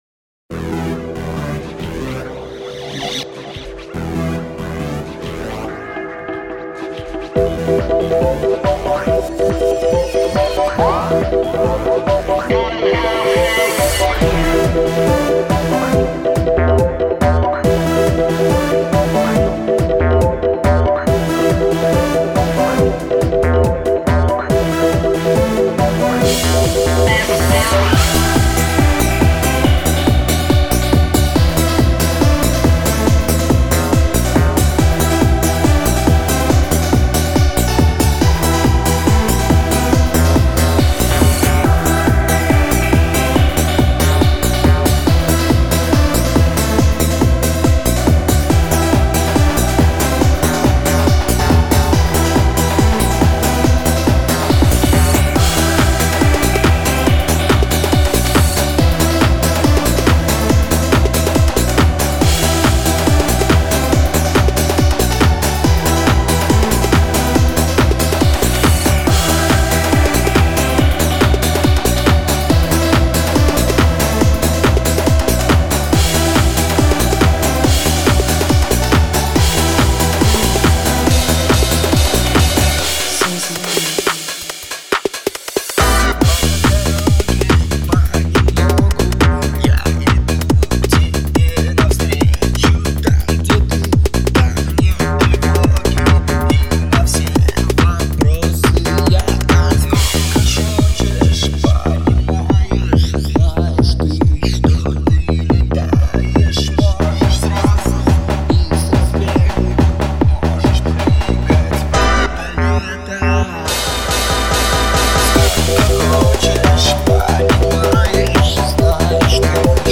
• Жанр: Танцевальная